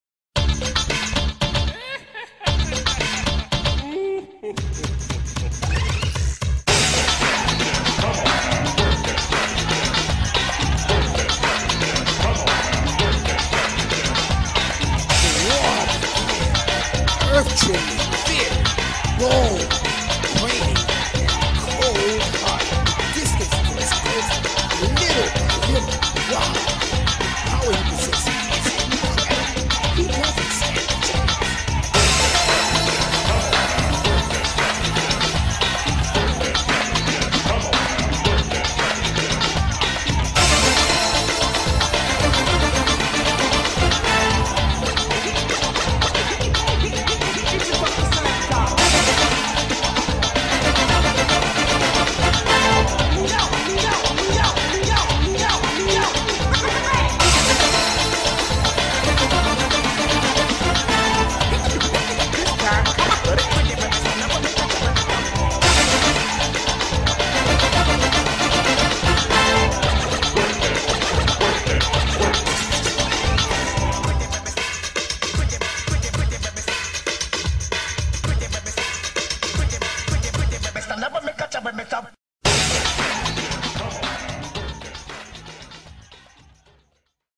BPM114--1
Audio QualityCut From Video